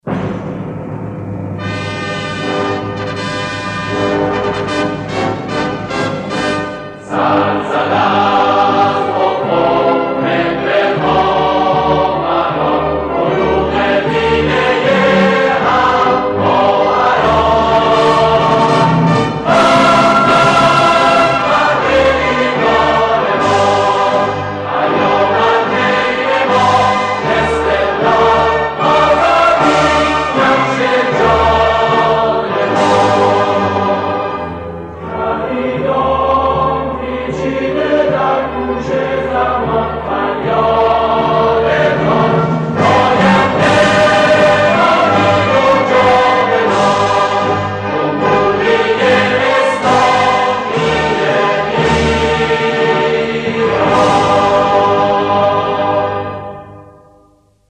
با کلام